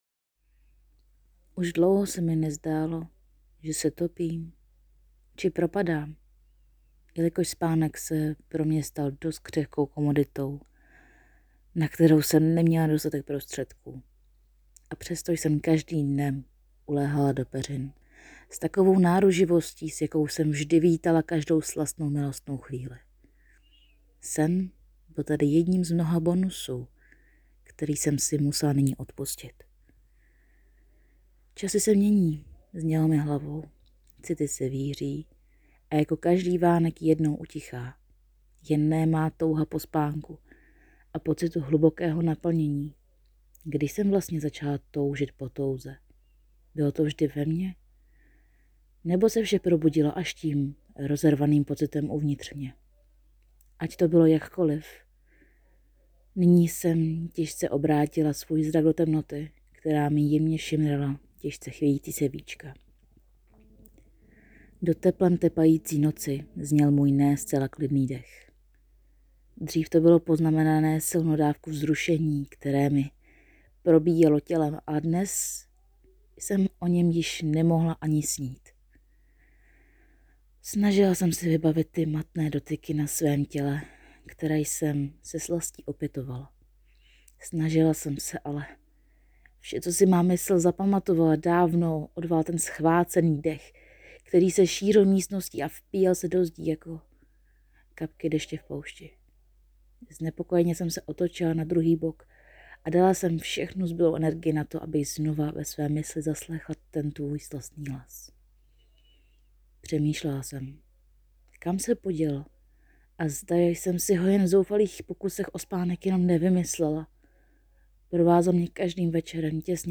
Prozaická miniatura » Ze života
Nádherný hlas... Jsem okouzlen...